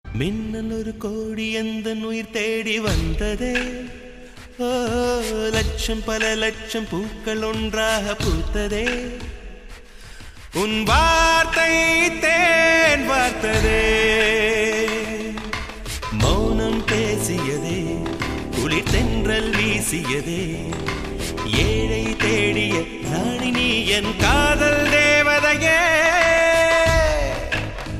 best flute ringtone download | love song ringtone
romantic ringtone